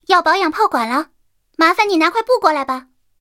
三号司令部语音3.OGG